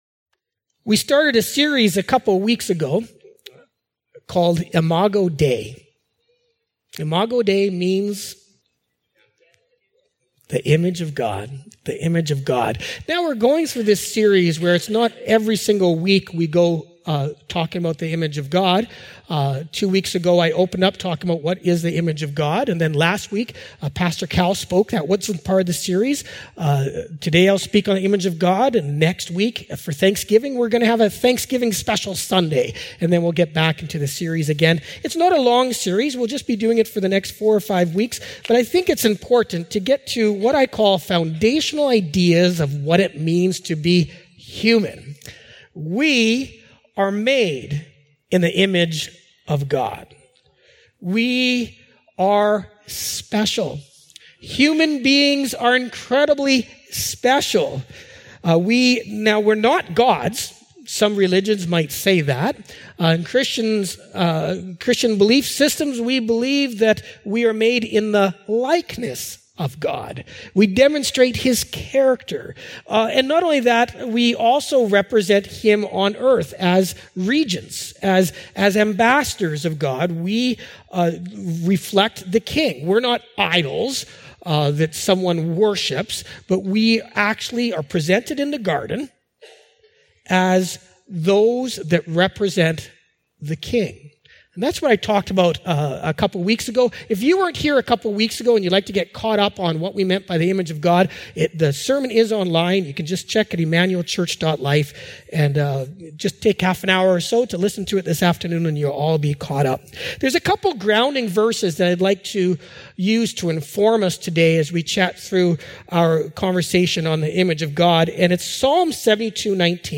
Sermons | Emmanuel Church